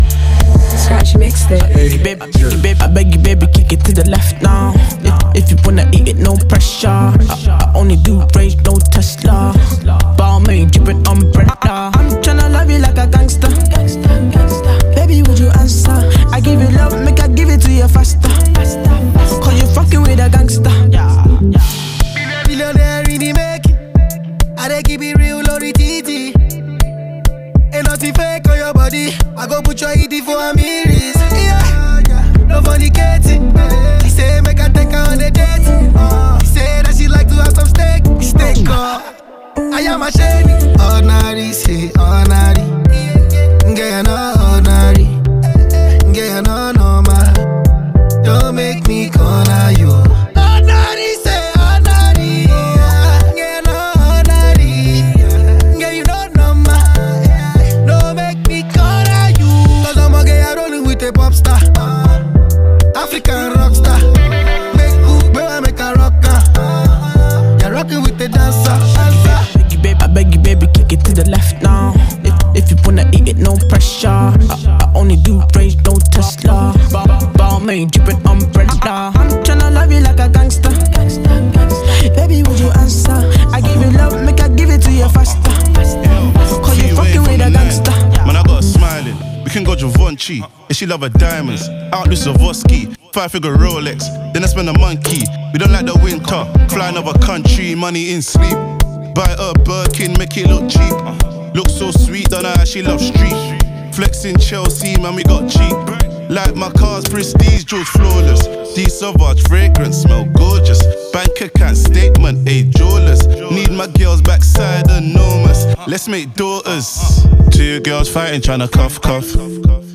From British Afrobeats pioneer